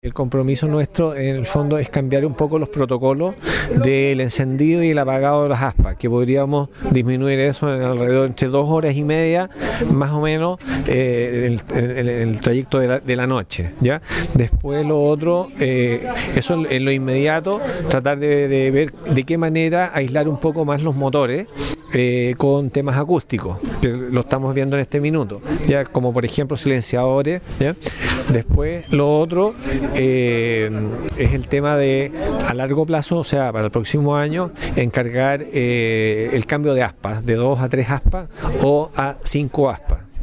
En conversación con «Teno Informado»